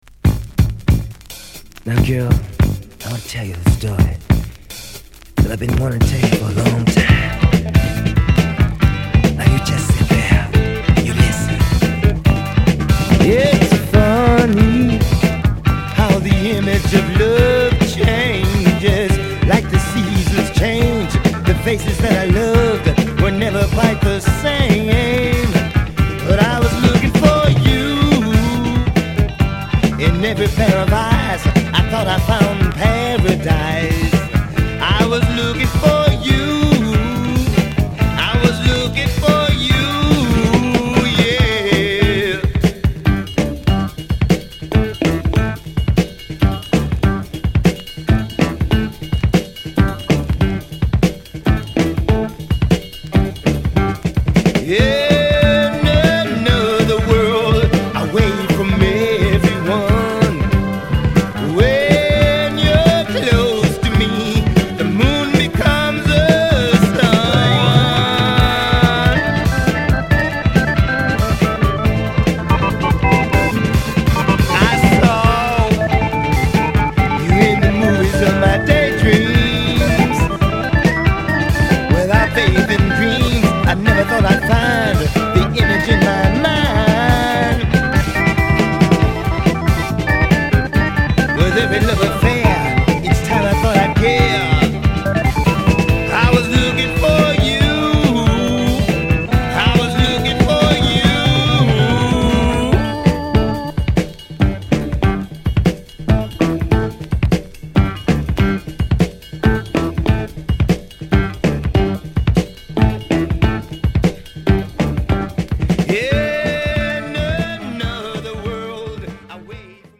Funk / Rare Groove france